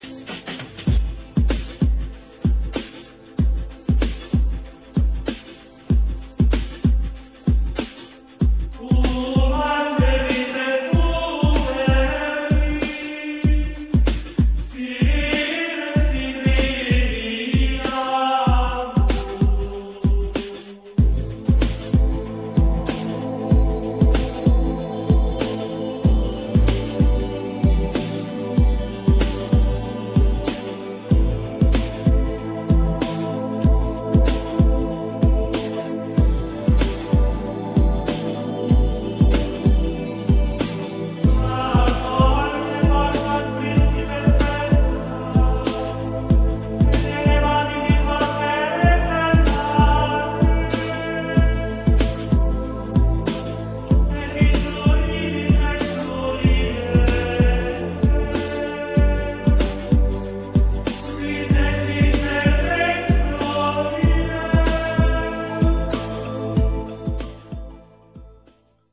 每当那袭人音符飘来，就有飞翔的感觉。